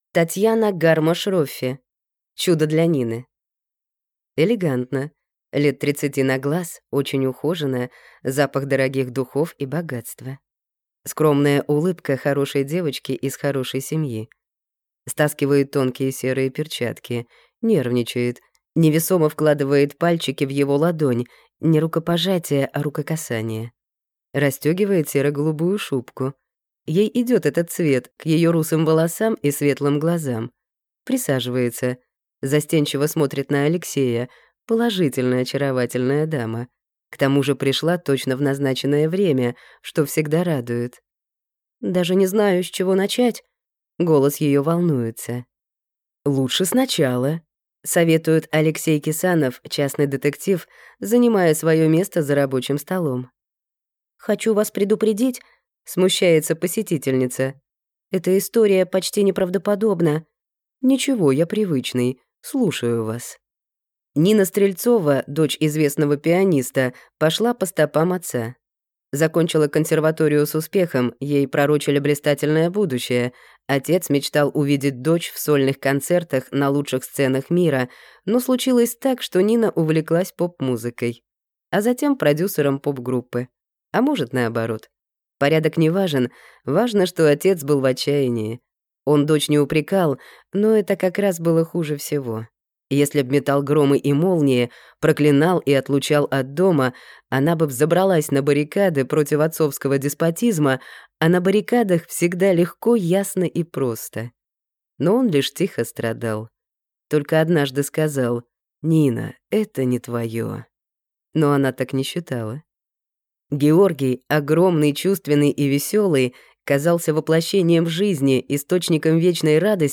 Аудиокнига Чудо для Нины | Библиотека аудиокниг